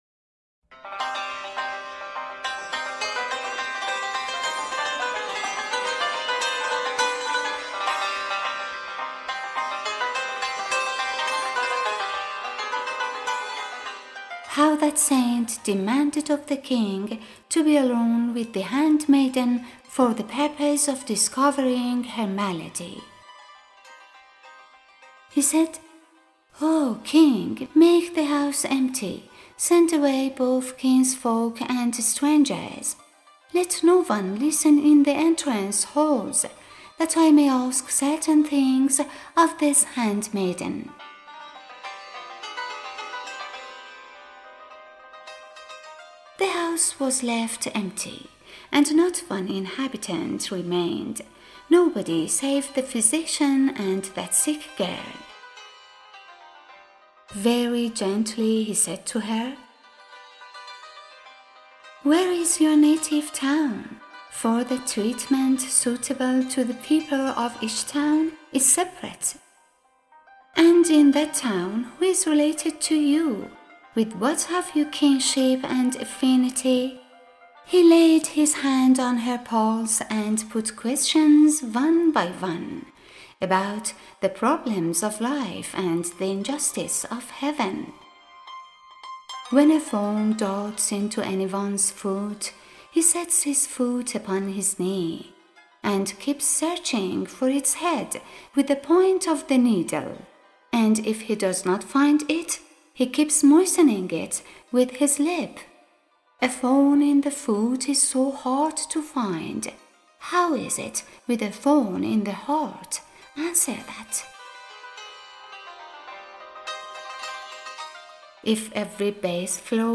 Narrator and Producer